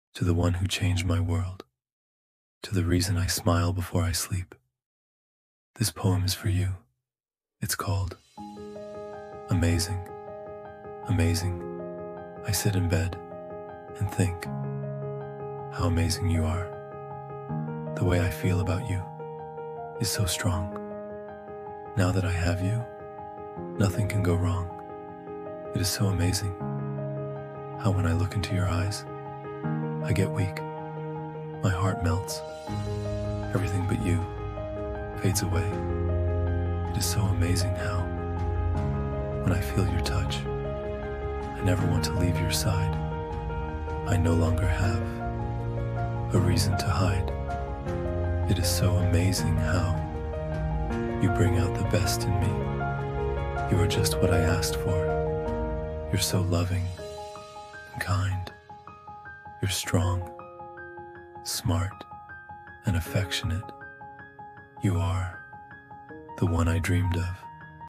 Fall In Love All Over Again With "Amazing": A Spoken Word Poem For Him.
amazing-love-poem-spoken-word.mp3